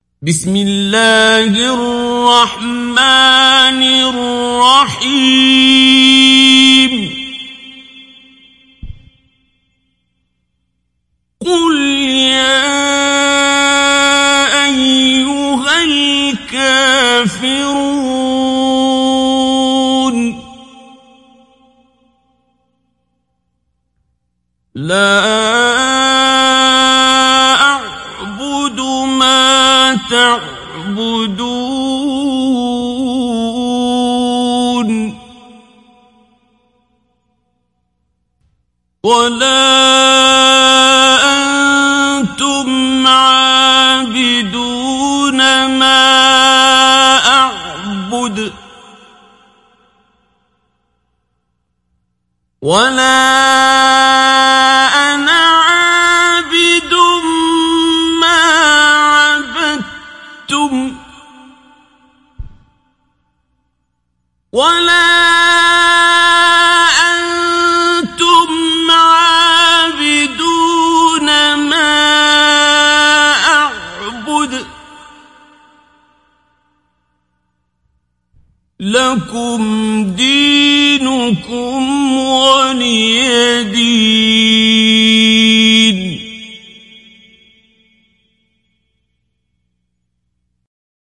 Download Surat Al Kafirun Abdul Basit Abd Alsamad Mujawwad
Mujawwad